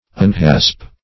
Unhasp \Un*hasp"\